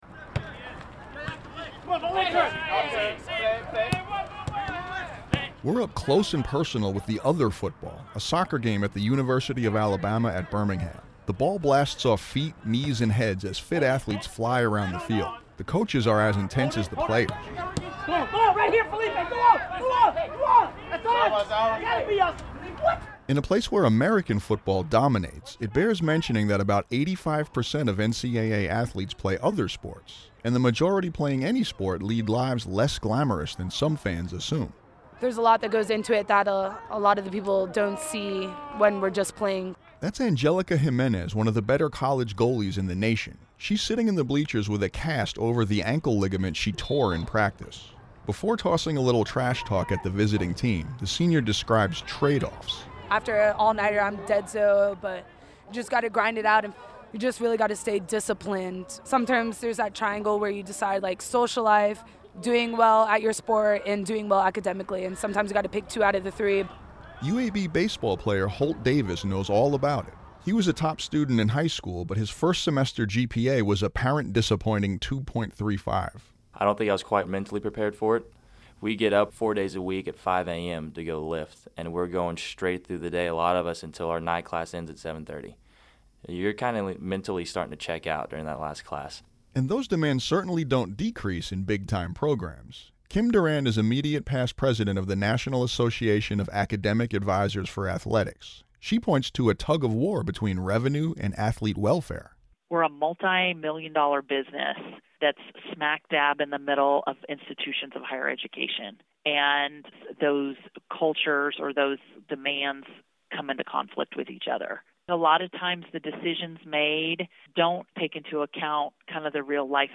We're up close and personal with the "other football" — a men's soccer game at the University of Alabama at Birmingham. You can hear the ball blasting off feet, knees and heads as fit athletes fly around the field and intense coaches bark at their players and the referee.
Back at the soccer game, loudspeakers blare T-shirt offers and upbeat music.
But just a short walk from the game, over by the batting cages, you're reminded as balls clink, over and over, off the bat of a lone athlete on a Sunday evening as the sun goes down.